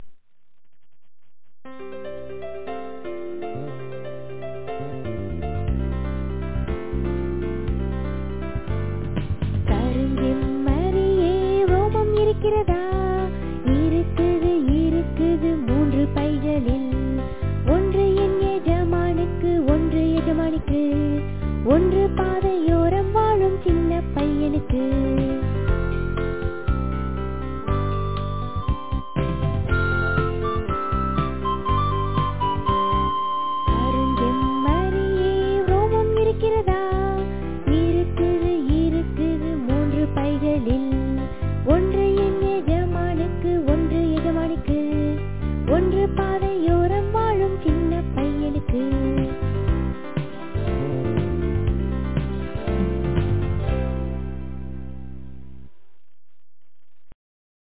In the final version, the sound recording and the singer's voice will be more professional.